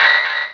pokeemerald / sound / direct_sound_samples / cries / anorith.aif